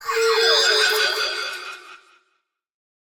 Minecraft Version Minecraft Version snapshot Latest Release | Latest Snapshot snapshot / assets / minecraft / sounds / mob / allay / idle_without_item3.ogg Compare With Compare With Latest Release | Latest Snapshot